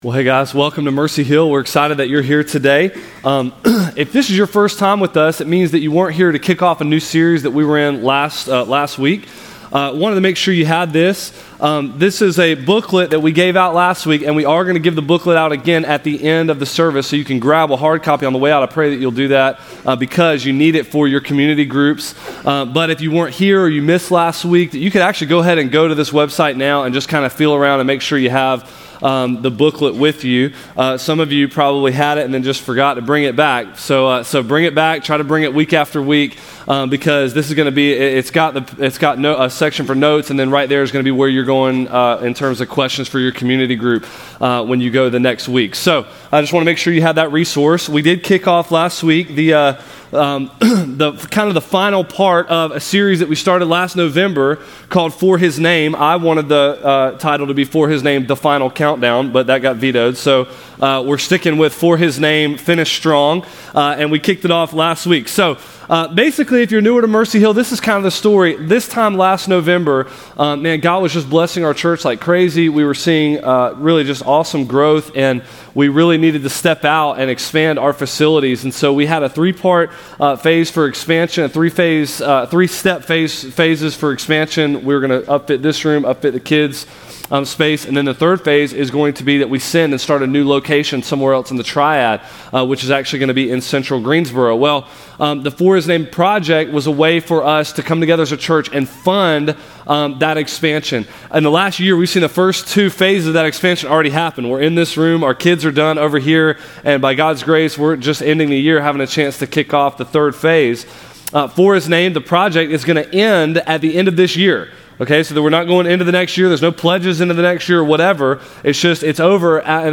The For His Name sermon series dives into what it means to do great things for the gospel.